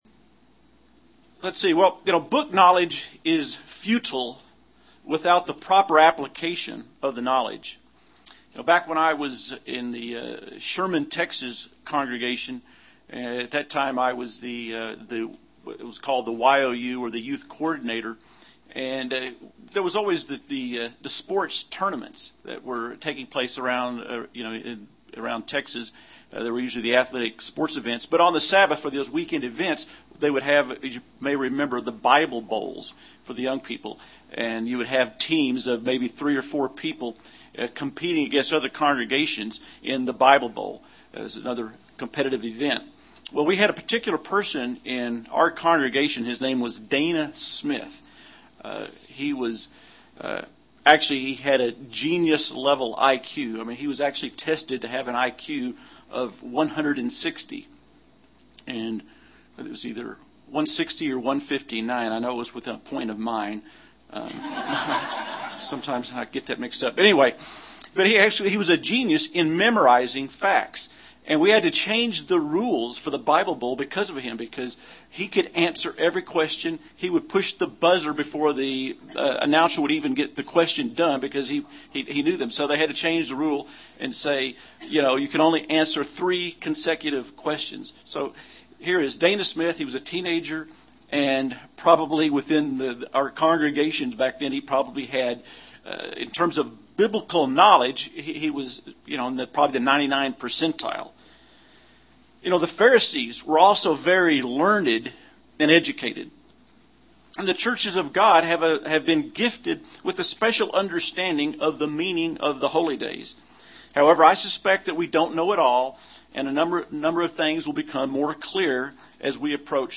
UCG Sermon Studying the bible?
Given in Albuquerque, NM